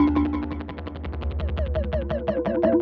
Index of /musicradar/rhythmic-inspiration-samples/85bpm
RI_DelayStack_85-13.wav